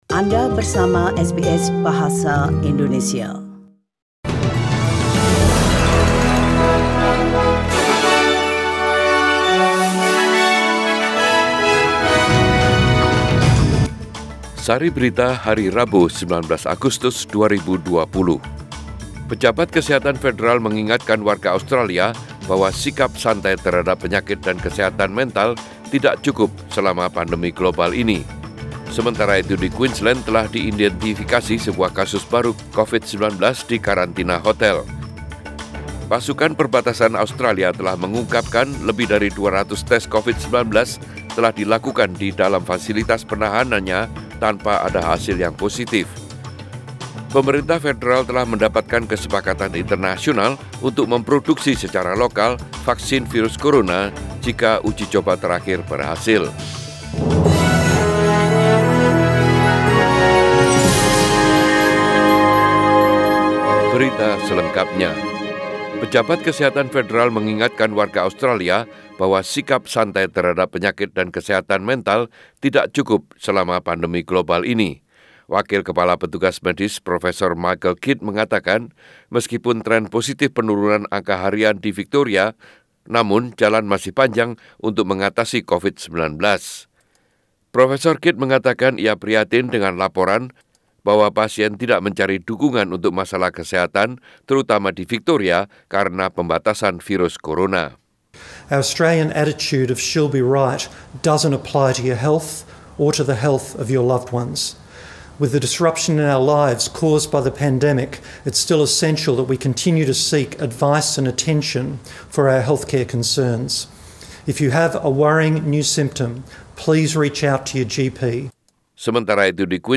Warta Berita Radio SBS Program Bahasa Indonesia - 19 Agustus 2020